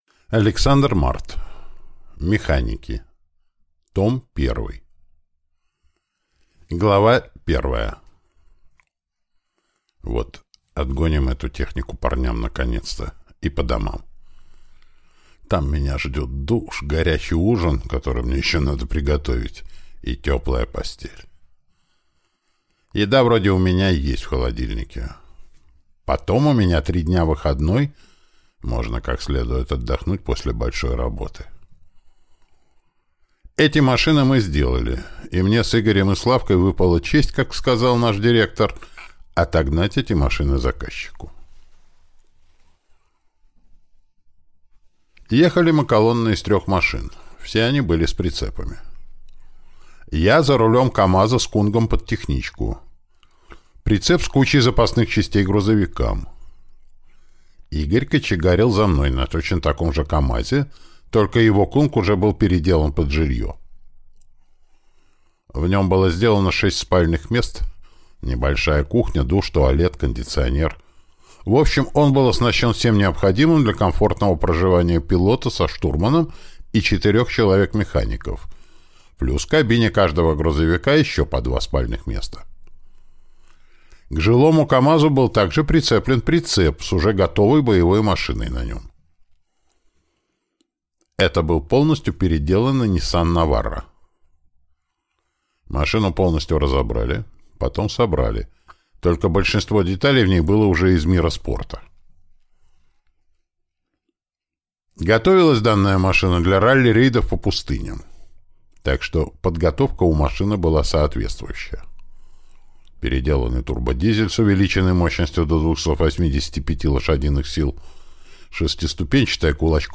Аудиокнига Механики. Том 1 | Библиотека аудиокниг